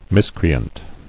[mis·cre·ant]